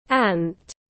Já no Reino Unido, essa pronúncia pode variar um pouco dependendo da região, mas no geral é bem parecida.